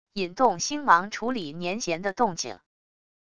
引动星芒处理黏涎的动静wav音频